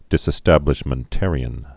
(dĭsĭ-stăblĭsh-mən-târē-ən)